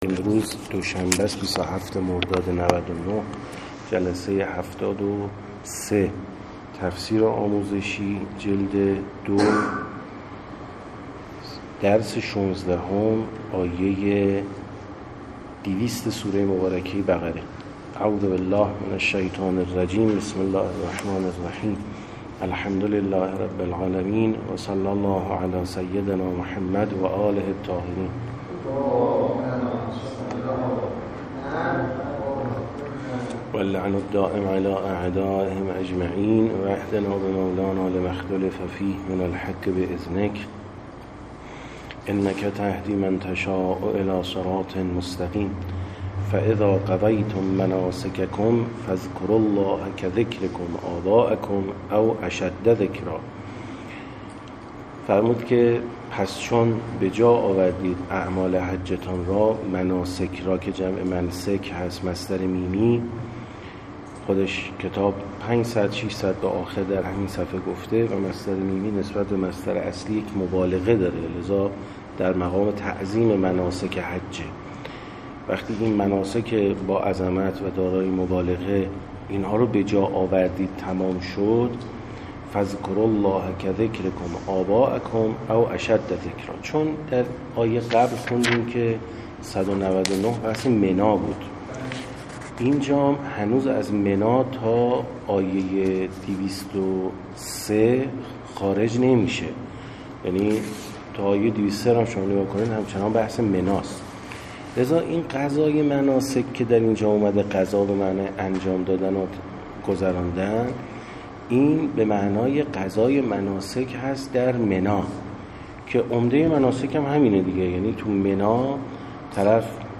73درس16ج2تفسیرآموزشی-ص151تا153-آیه200بقره.MP3